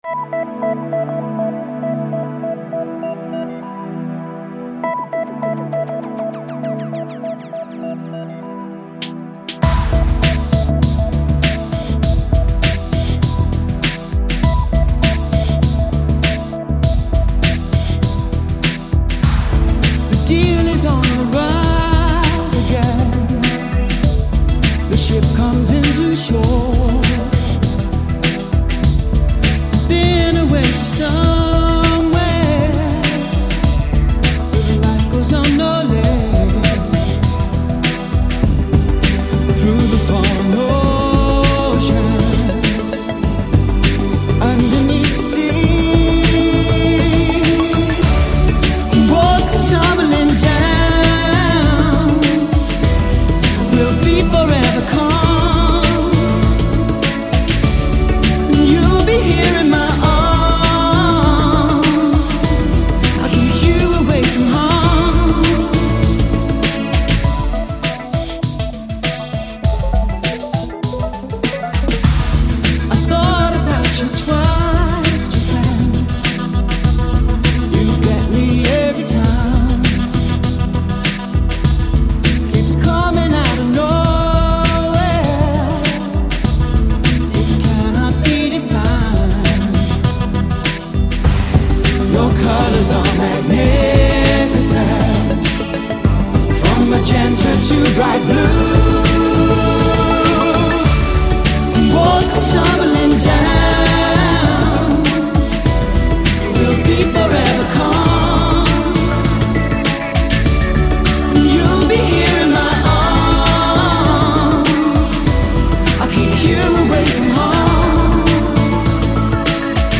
Audio: Música ambiental: